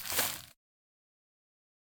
footsteps-single-outdoors-002-02.ogg